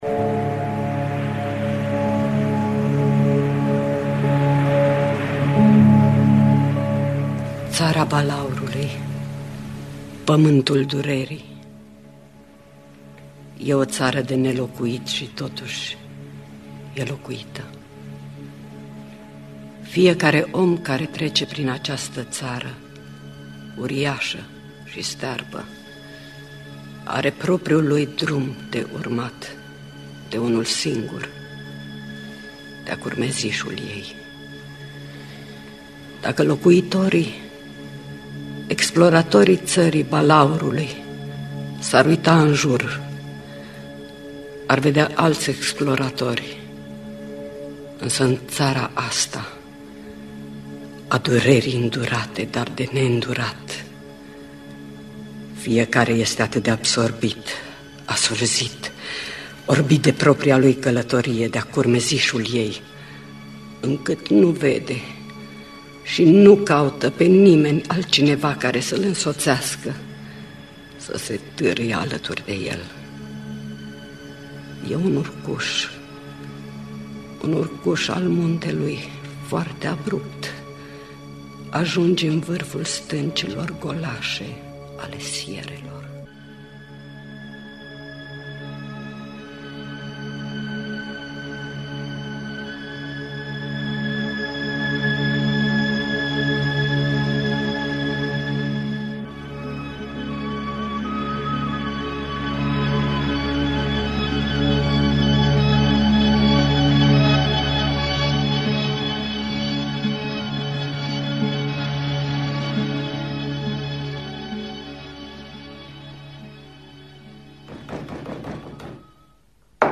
Nu știu cum va fi ziua de mâine sau Țara balaurului de Tennessee Williams – Teatru Radiofonic Online